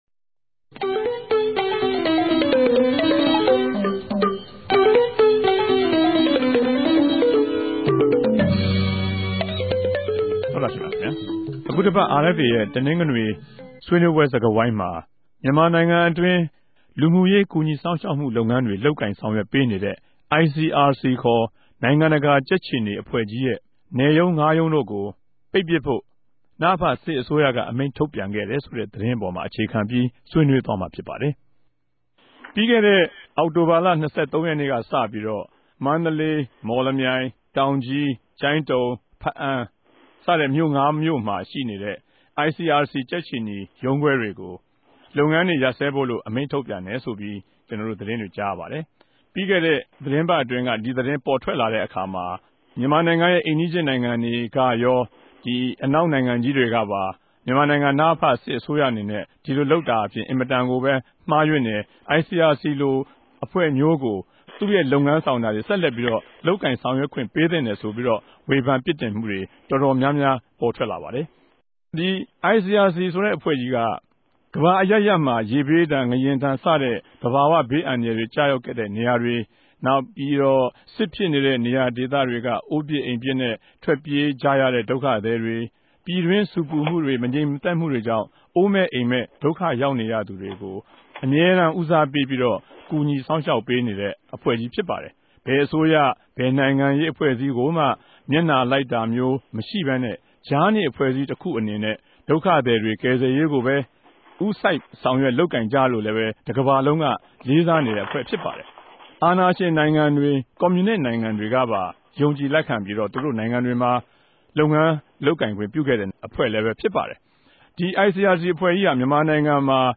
တပတ်အတြင်း သတင်းသုံးသပ်ခဵက် စကားဝိုင်း (၂၀၀၆ ဒီဇင်ဘာလ ၂ရက်)
ပၝဝင်ဆြေးေိံြးမဲ့ ပုဂ္ဂိြလ် သုံးဦးကို ဝၝရြင်တန်္ဘမိြႚ RFA စတူဒီယိုထဲကနေ